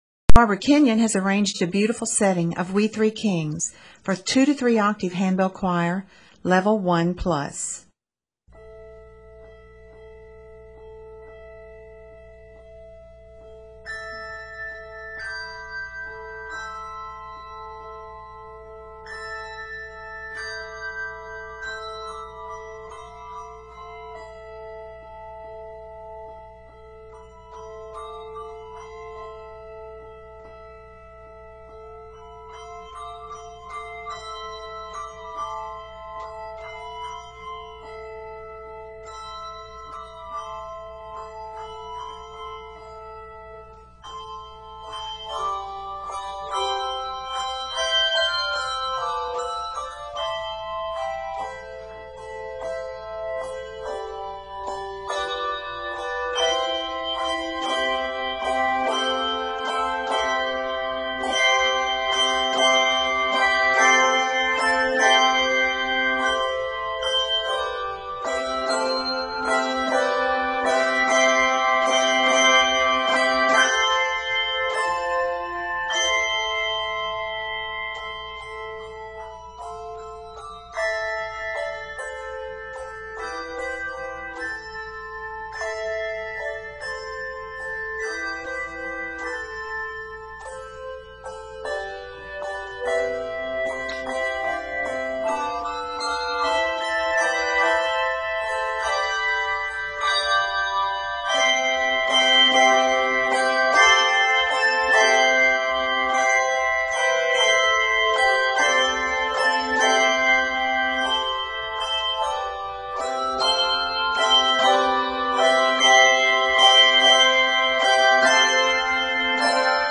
Mallets and LV techniques are used in this piece.